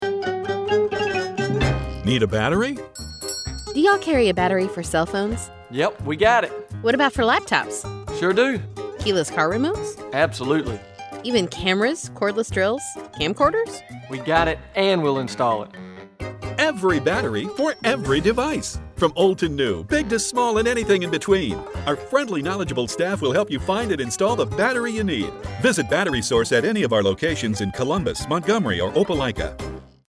Battery Source Radio Ad
We wanted a fun, creative way to tell people that Battery Source has a battery for everything – “We got it” is the hook for this ad. Using only their local employee’s as the talent for the ad, we re-created the experience of customers who bring in items from phones to golf carts.